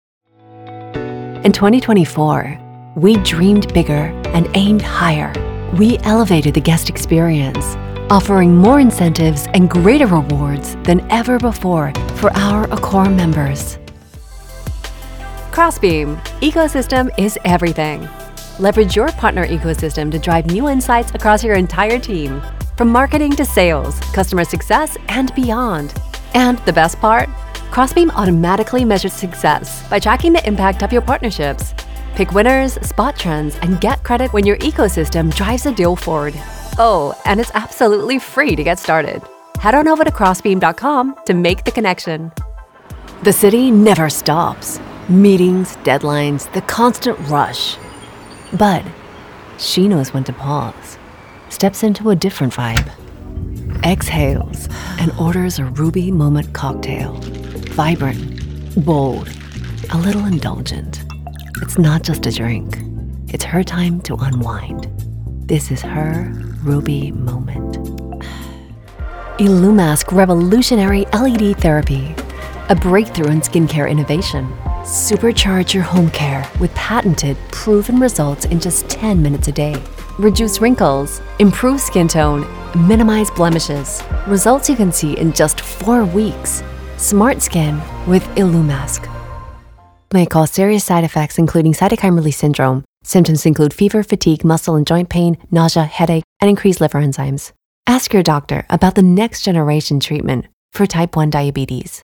Female
English (North American), English (Australian)
I love to create warm, sincere, engaging voices that are natural, intelligent, vibrant, witty and charming, with all those subtle nuances!
Usa Accent Various Media
All our voice actors have professional broadcast quality recording studios.